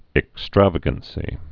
(ĭk-străvə-gən-sē)